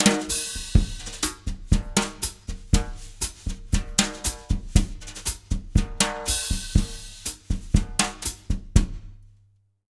120BOSSA05-L.wav